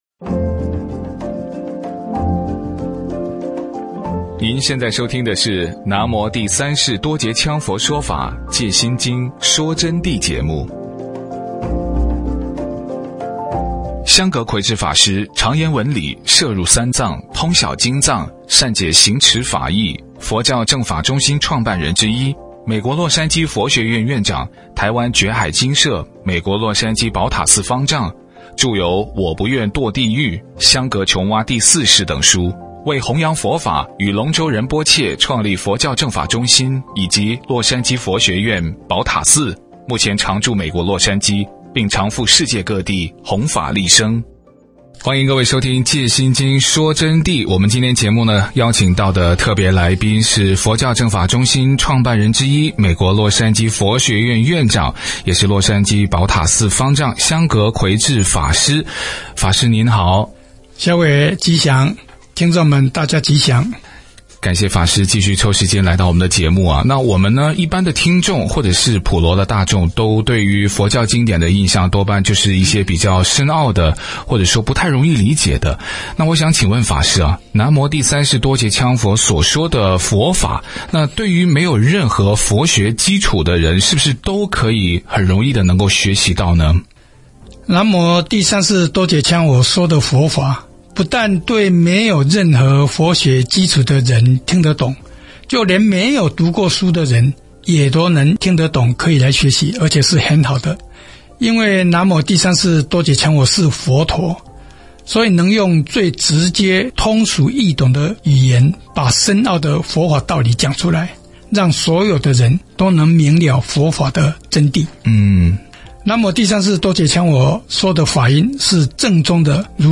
佛弟子访谈（五）什么样的人可以学到南无羌佛的佛法？